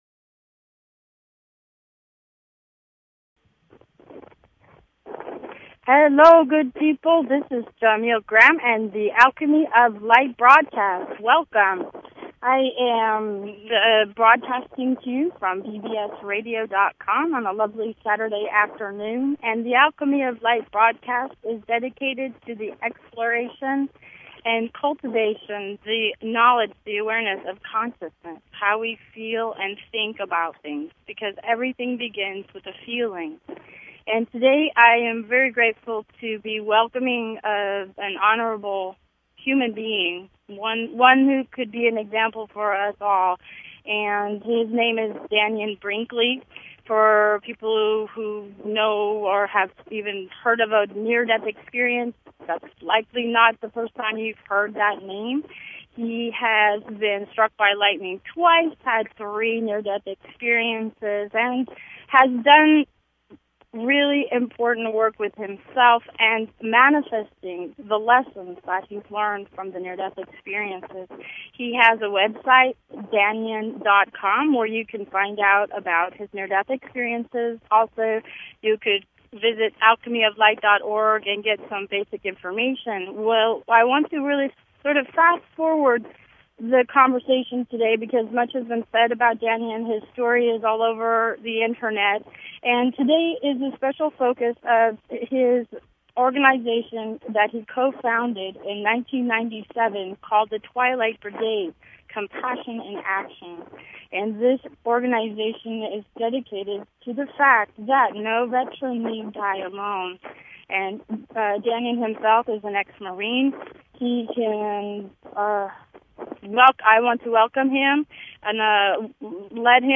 Talk Show Episode, Audio Podcast, Alchemy_of_Light and Courtesy of BBS Radio on , show guests , about , categorized as
Show Headline Alchemy_of_Light Show Sub Headline Courtesy of BBS Radio An extraordinary interview with Dannion Brinkley inviting us to participate in the changing consciousness - the dawning of the new world and the closing of the old world.